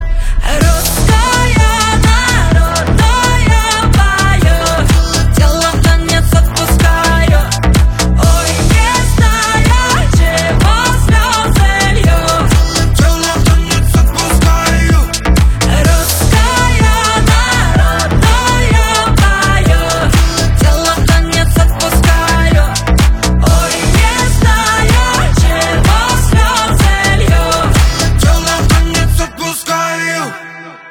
поп
танцевальные
басы